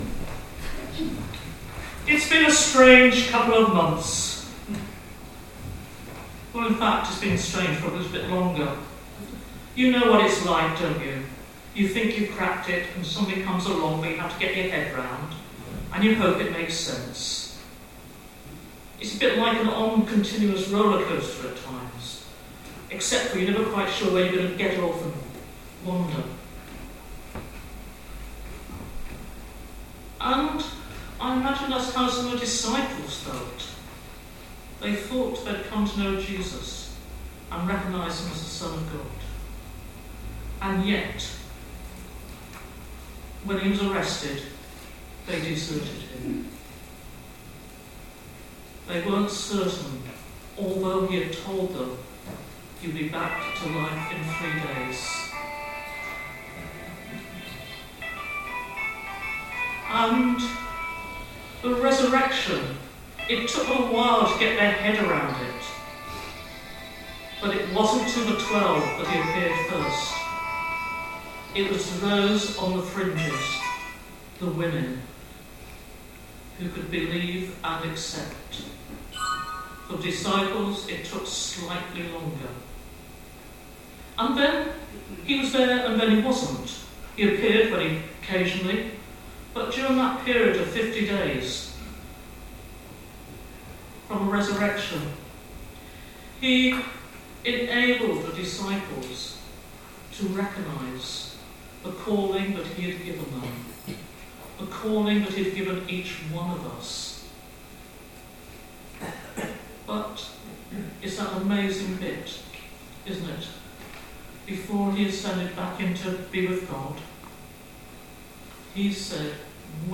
Sermon for Trinity Sunday - Proverbs 8.1-4, 22-31, Romans 5.1-5 and John 16.12-15